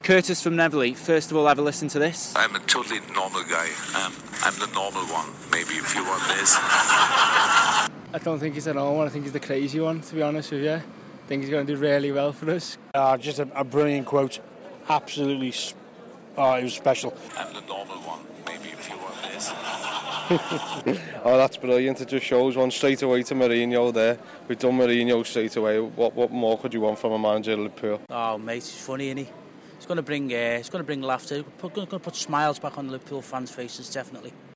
Jurgen Klopp described himself as the 'normal one' as he was unveiled as the new manager of Liverpool. We've be out getting reaction from fans about today's most talked about moment.